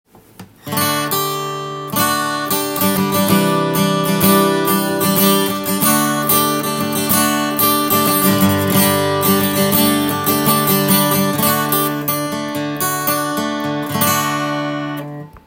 試しに弾かせて頂きました。
マーチン独特のミドルレンジとレトロな音がメイドインメキシコでも